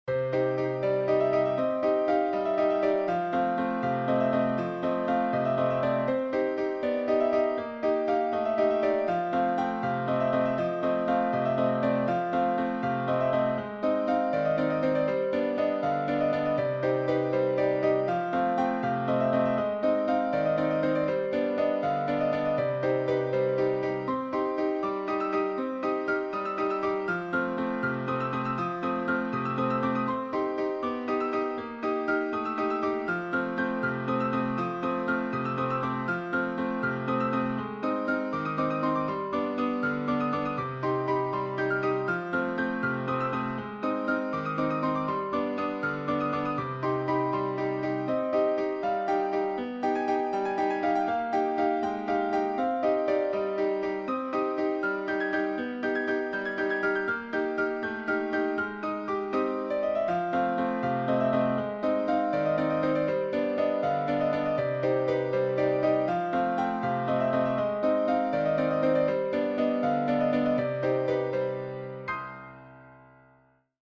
Müəllif: Azərbaycan Xalq Rəqsi